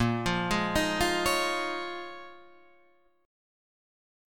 A#m11 Chord